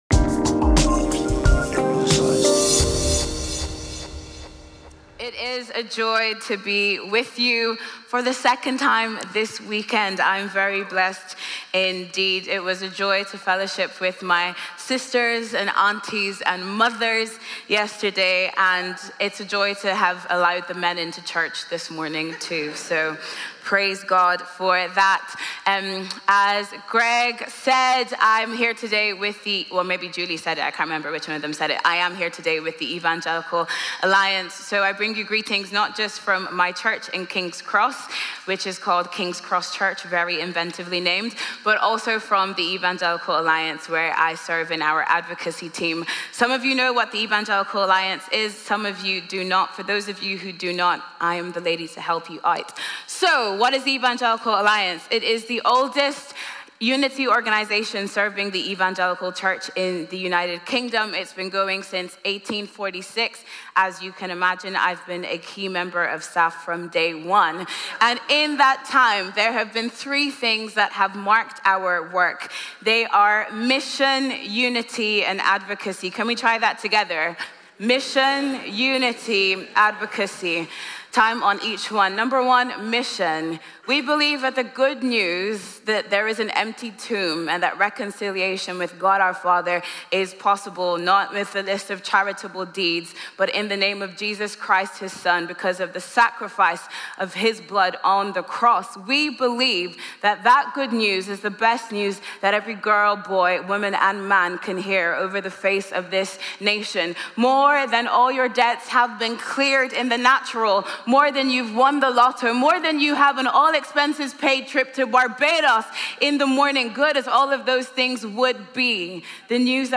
The Morning Sermon 23.03.25 - All Saints Peckham
Audio Sermon